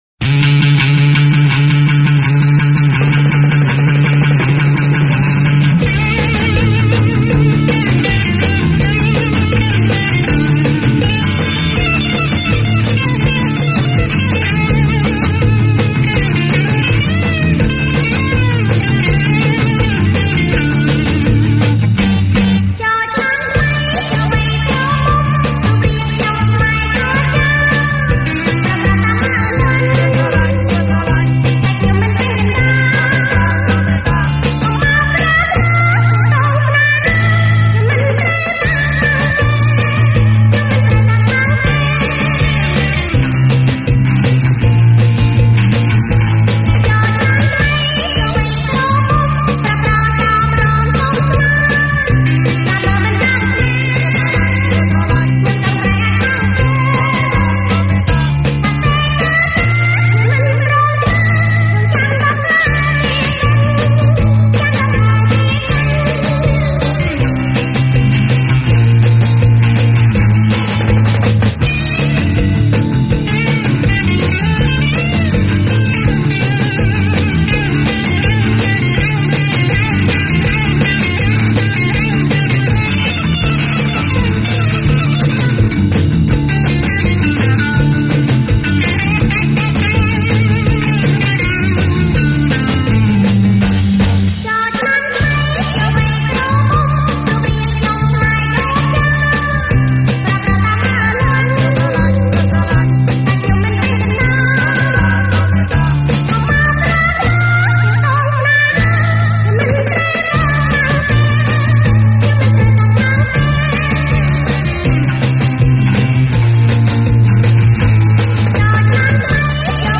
本期音乐为柬埔寨（Cambodia）摇滚音乐专题。
这些歌曲风格有点类似美国六十年代的Rock Billy（乡村摇滚乐）曲风。